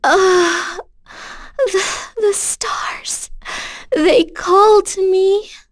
Yuria-Vox_Dead_b.wav